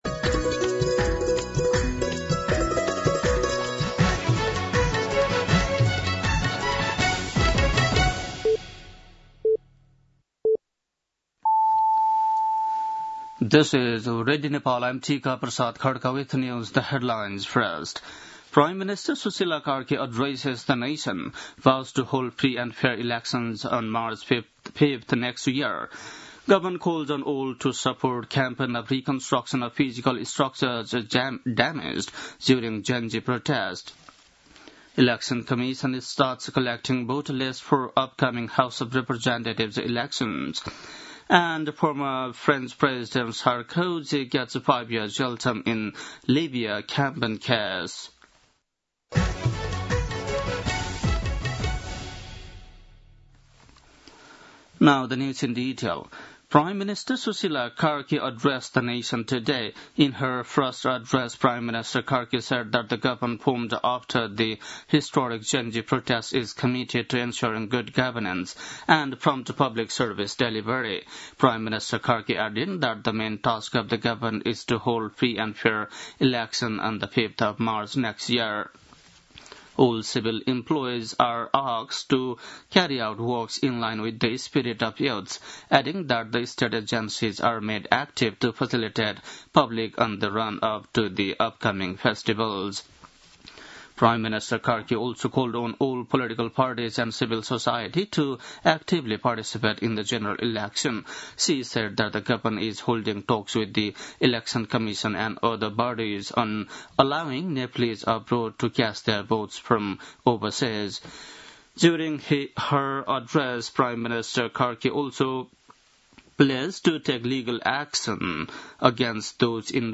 बेलुकी ८ बजेको अङ्ग्रेजी समाचार : ९ असोज , २०८२
8-pm-news-6-9.mp3